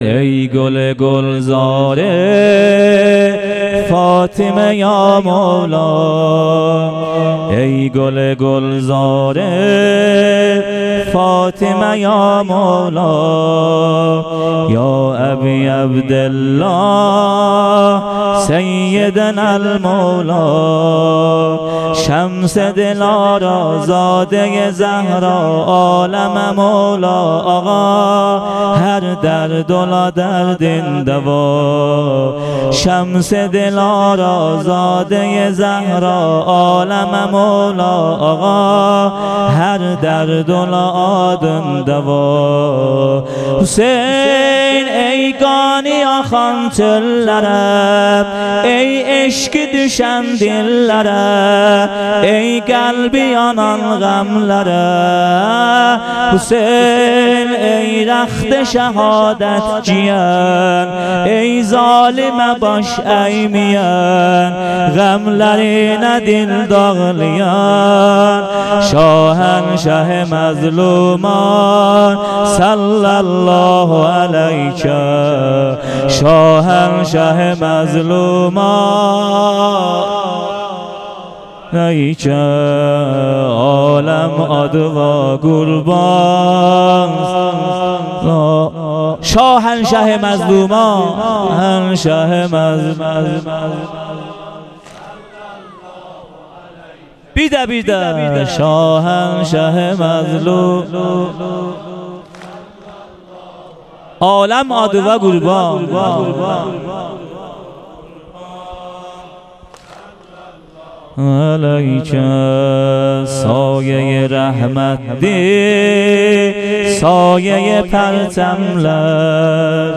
شب پنجم محرم 98 - بخش دوم سینه زنی (واحد)